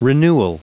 Prononciation du mot renewal en anglais (fichier audio)
Prononciation du mot : renewal